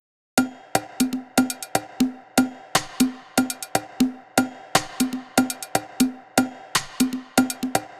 Bp Congas Loop.wav